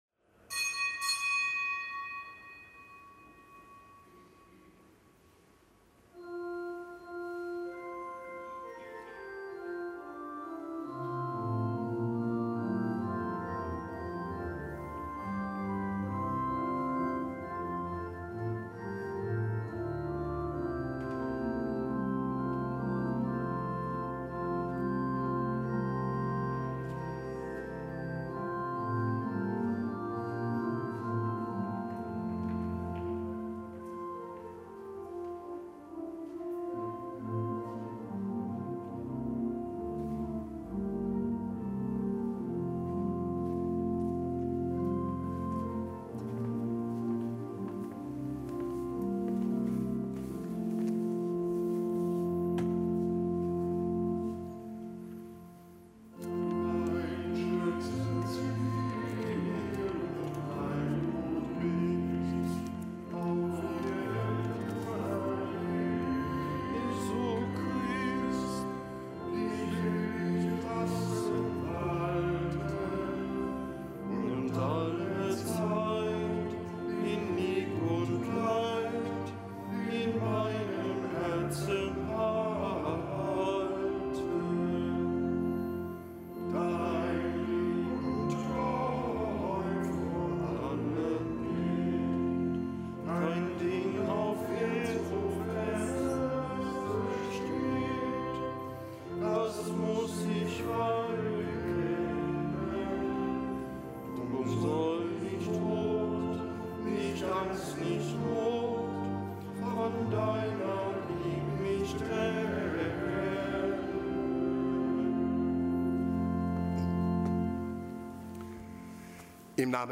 Kapitelsmesse am Gedenktag der Heiligen Elisabeth
Kapitelsmesse aus dem Kölner Dom am Gedenktag der Heiligen Elisabeth, Landgräfin von Thüringen.